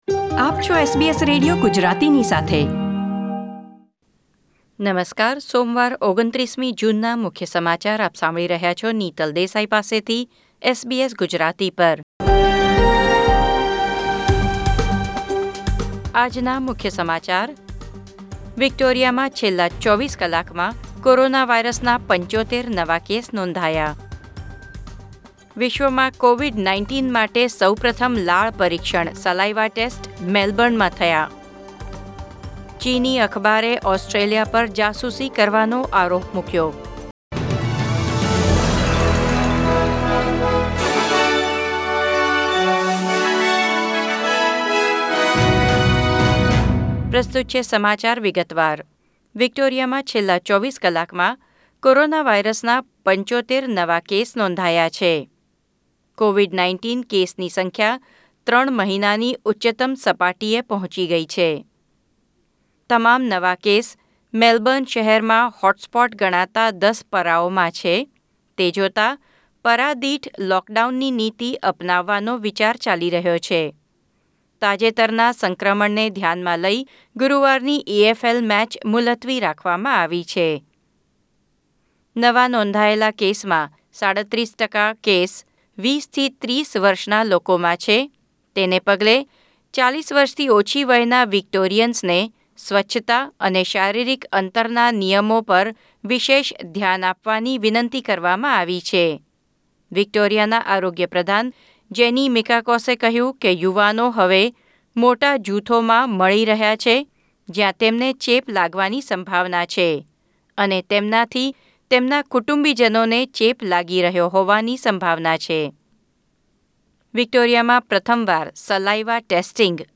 SBS Gujarati News Bulletin 29 June 2020